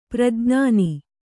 ♪ prajñāni